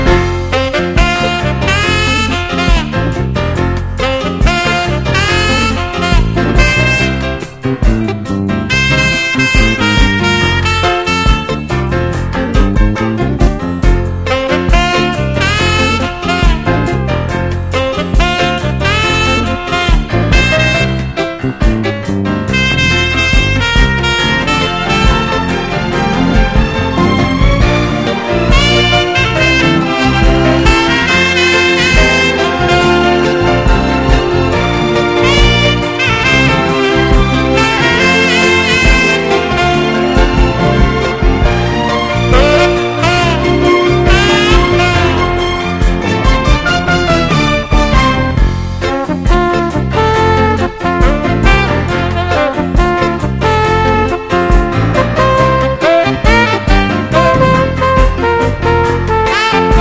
EASY LISTENING
ヒップホップ的なビートとサンバ風なビートを行き来する